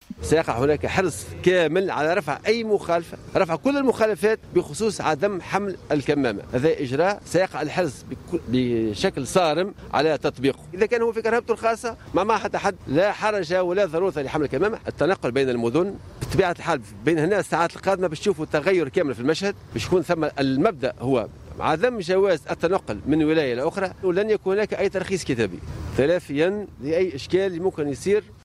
وبين وزير الداخلية خلال زيارات ميدانية شملت خصوصا محطات النقل الرئيسية بالعاصمة وعددا من النقاط بالطريق السريعة أ1، لمتابعة تنفيذ القرارات المتعلقة بمعاضدة المجهود الوطني لمكافحة فيروس كورونا من طرف وحدات الأمن والحرس الوطنيين، أنه سيتم في الساعات القادمة تغير المشهد بالكامل، حيث لن يكون هناك تراخيصا كتابية، مع الحرص بشكل صارم على تطبيق أجراء حمل الكمامات بالفضاءات العامة ومحطات ووسائل النقل العمومي.